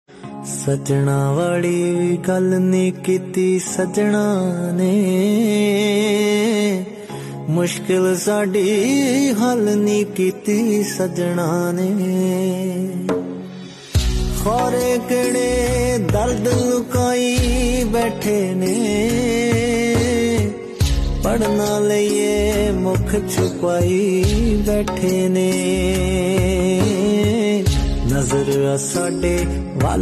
melodious Singer